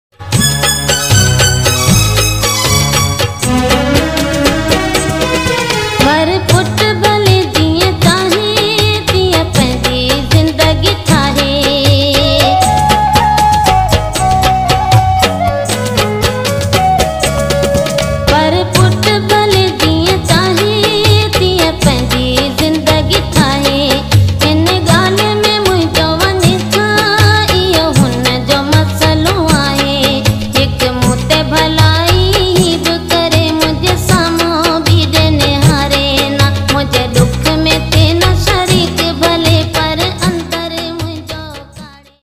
Sindhi Song